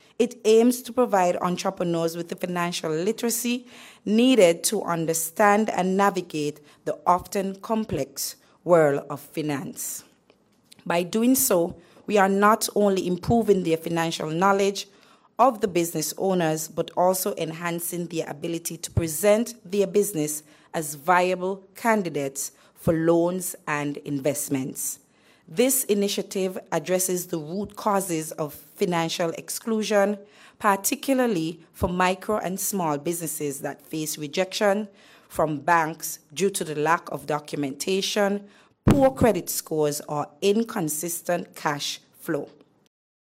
PS Delrine Taylor.